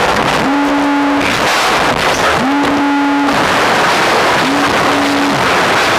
mobile phone passing on a vibrating sub
mobile-phone-passing-on-a-vibrating-sub.mp3